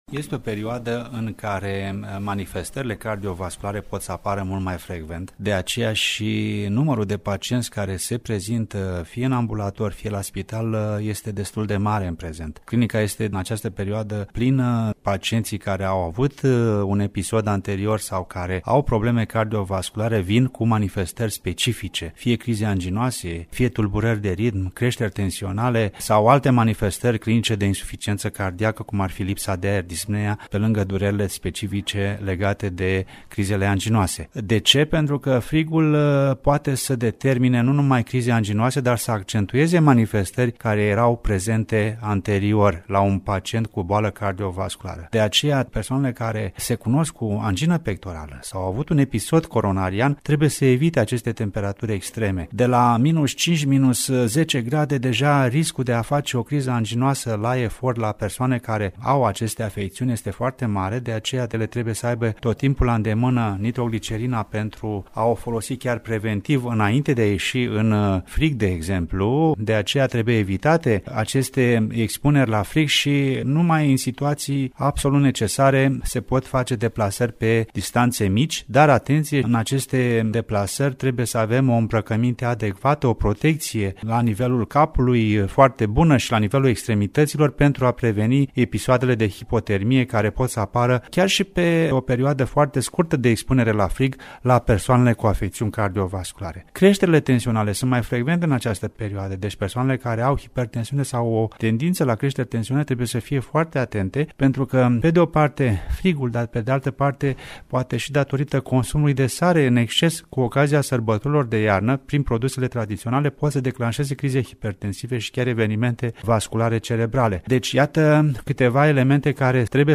Aflați azi cum ne alimentăm corect iarna, cum ne apărăm de hipotermie, care sunt riscurile deszăpezirii pentru sănătatea inimii şi cum prevenim aceste riscuri. Interviu